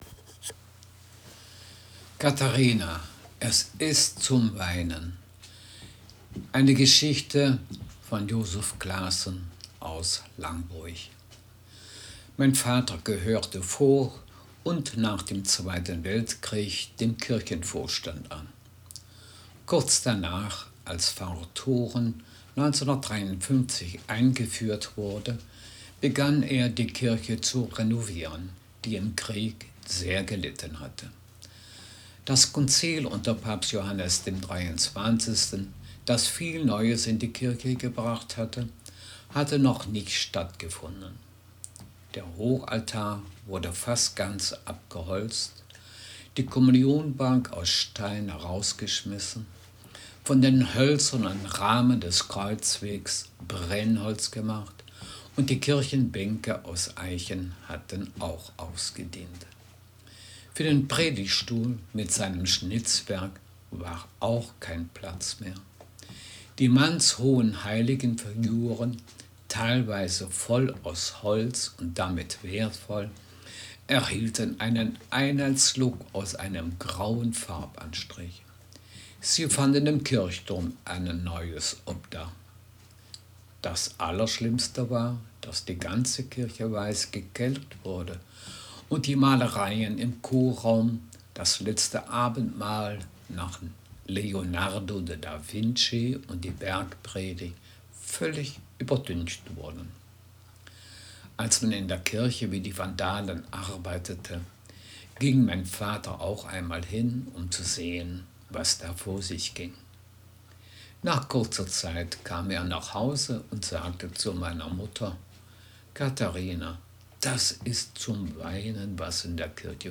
Text hochdeutsch